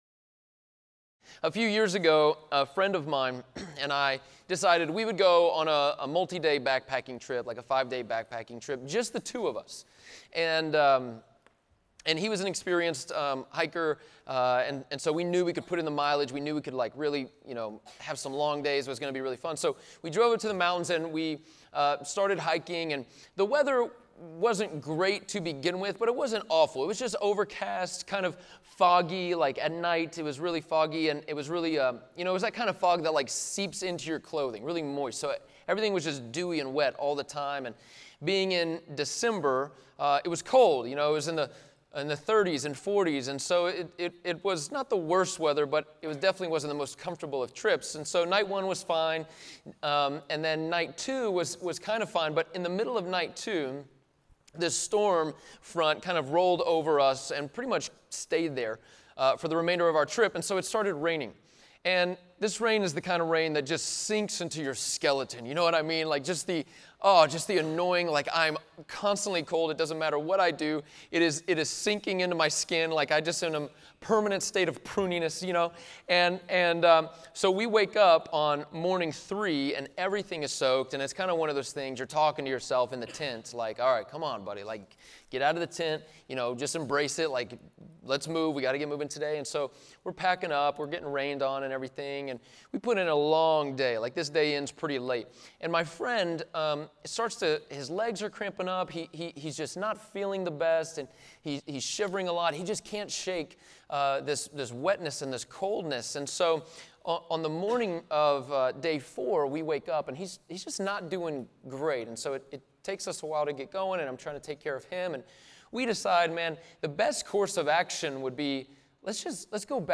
John 3:1-15 Audio Sermon Notes (PDF) Ask a Question Scripture: John 3:1-15 Being lost—you know that feeling?